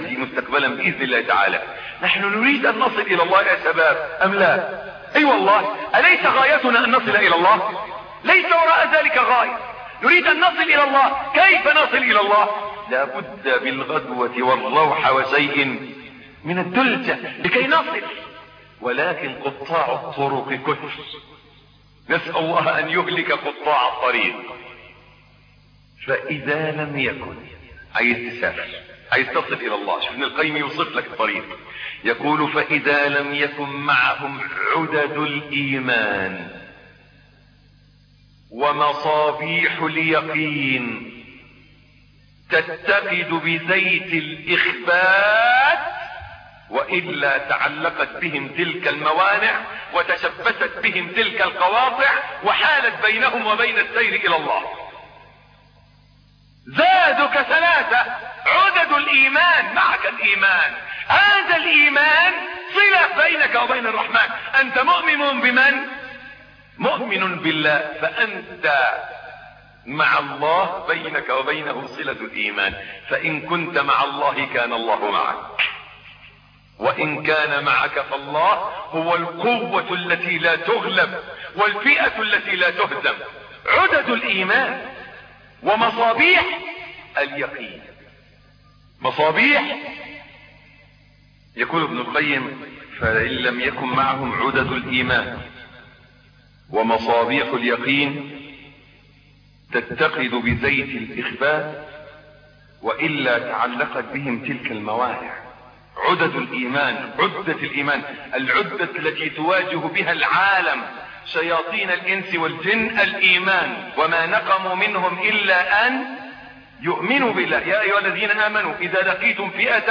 الدرس الثالث - فضيلة الشيخ محمد حسين يعقوب